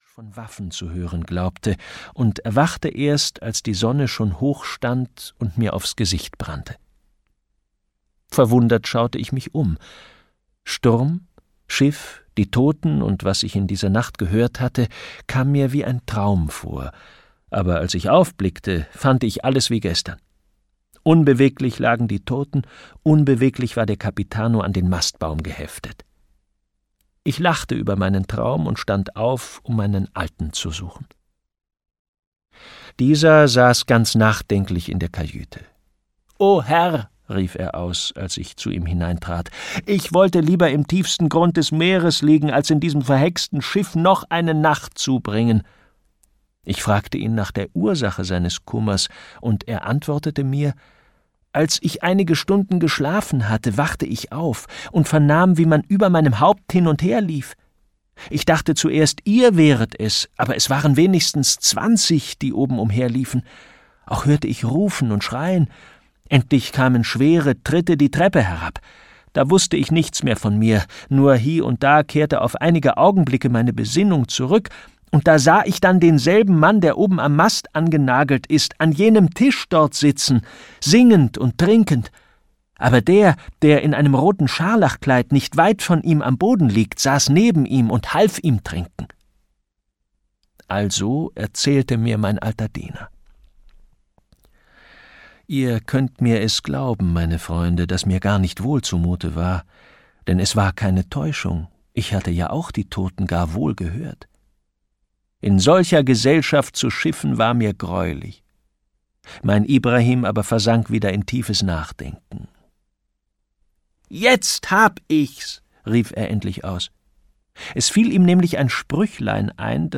Die Geschichte von dem Gespensterschiff - Wilhelm Hauff - Hörbuch